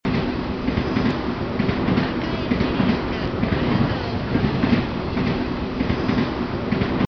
騒音下で聞き取りやすいように音声を加工処理した例　［修士学生の研究よ り］
（少し音量を上げて注意して聞いて下さい．騒音や音声の音量は，どちらも 各々同じです．）
加工済音声